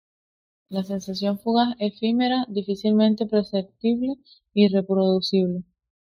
sen‧sa‧ción
/sensaˈθjon/